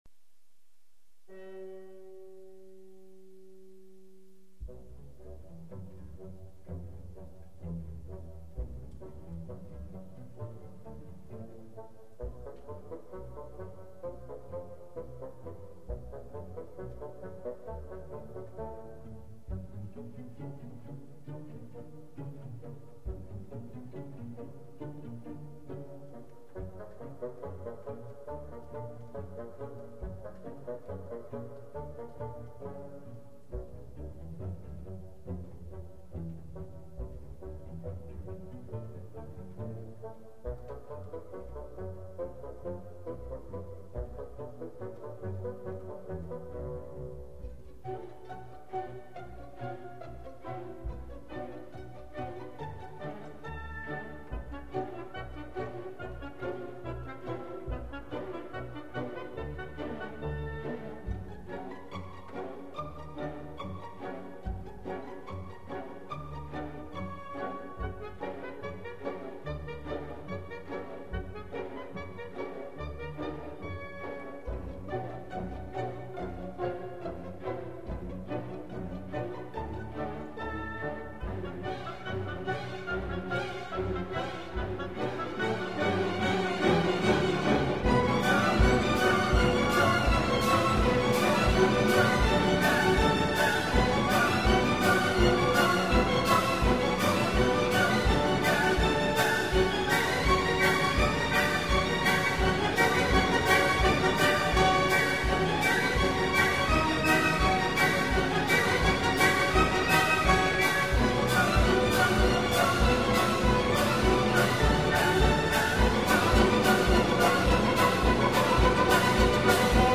Not to rain on the classical music parade here but I would sugggest getting a community member to do the soundtrack.